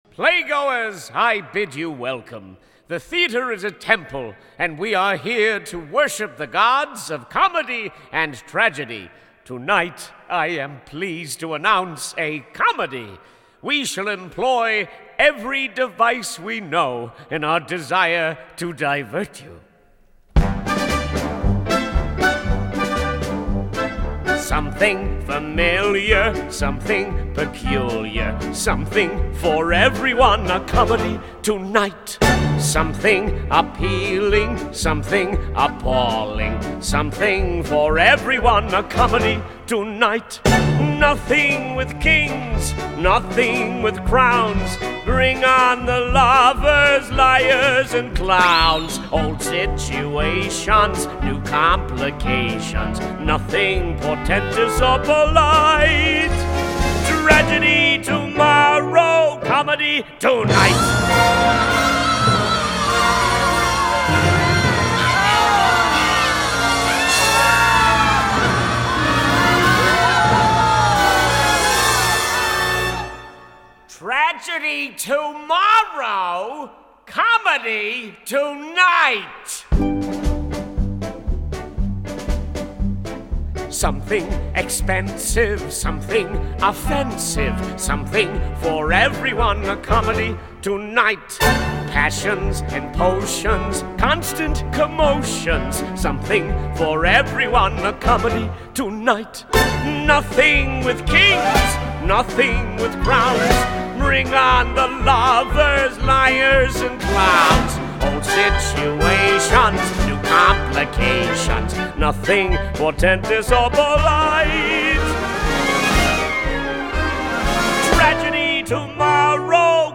1962   Genre: Musical   Artist